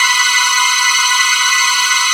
Synth Lick 50-03.wav